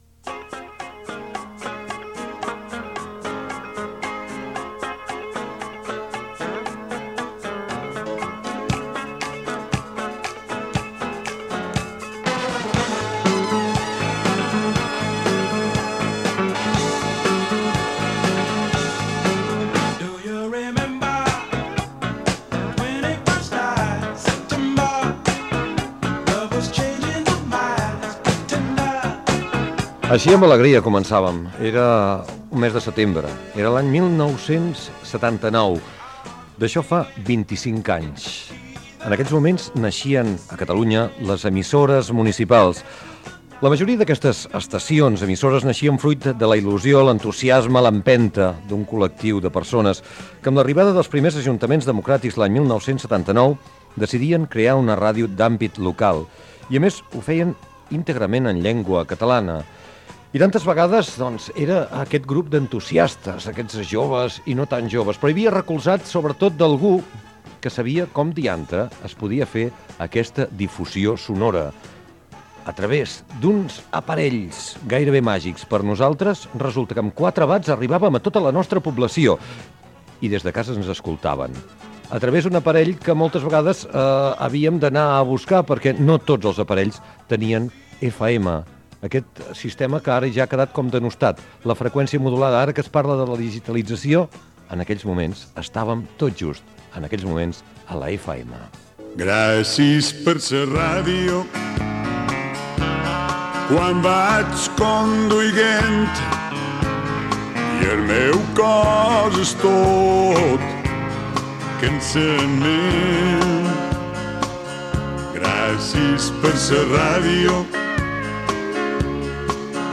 Inici i presentació del programa que recorda el naixement de les emissores municipals l'any 1979.
Divulgació